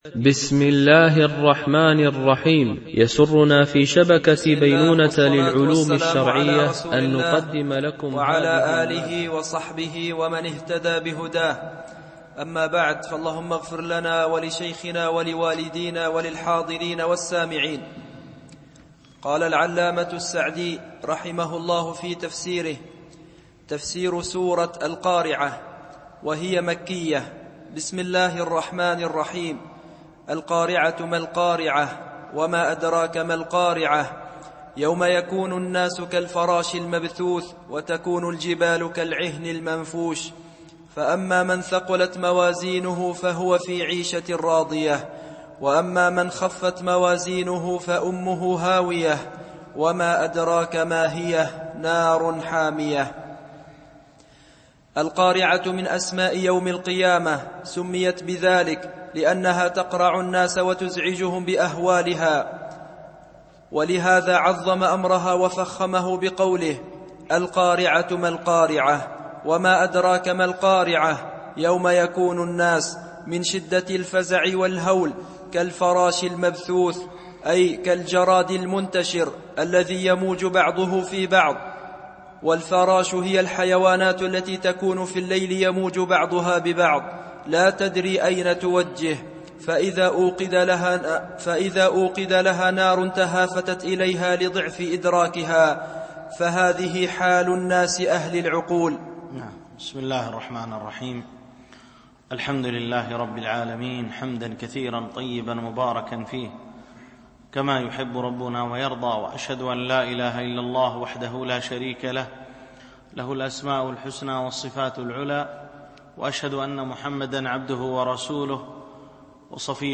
قصار المفصّل من تفسير السّعدي - الدرس 5 (سورة: القارعة ، التكاثر ، العصر )
MP3 Mono 22kHz 32Kbps (CBR)